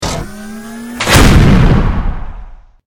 battlesuit_grenade.ogg